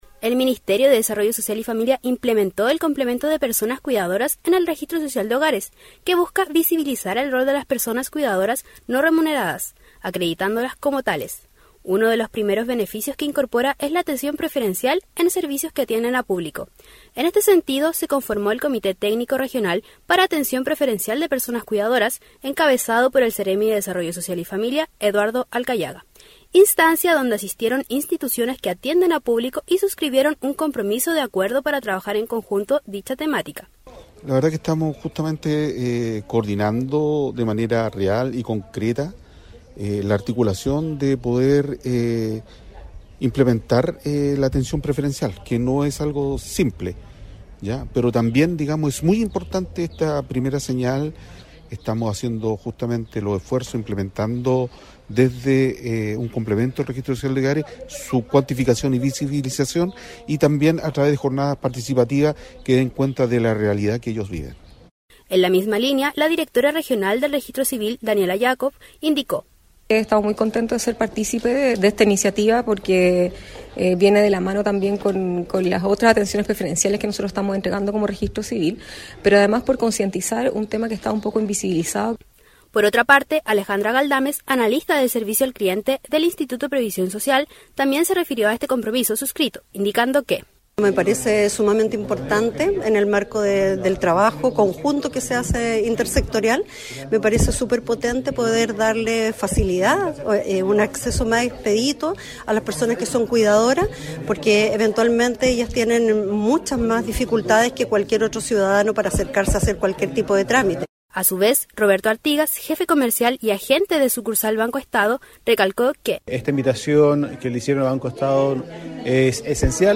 Despacho-radial_-Instituciones-reafirman-compromiso-para-brindar-atencion-preferencial-a-personas-cuidadoras.mp3